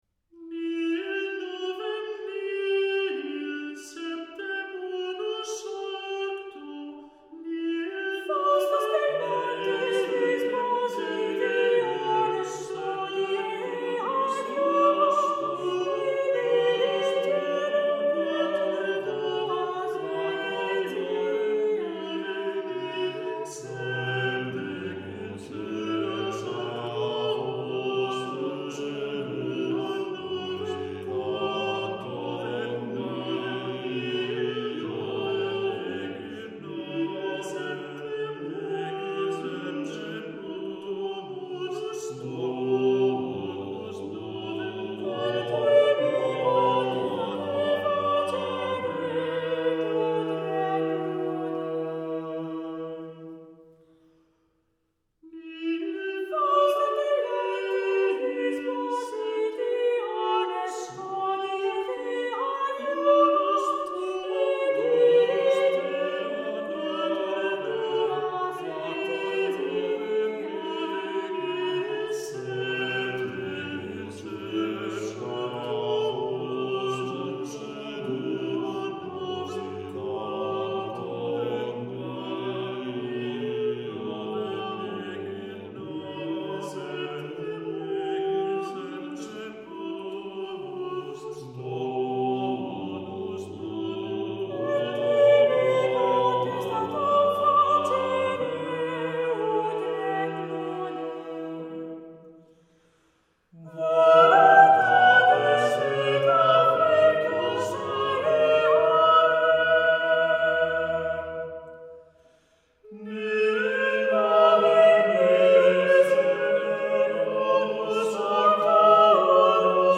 has provided us with two more hymns